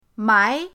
mai2.mp3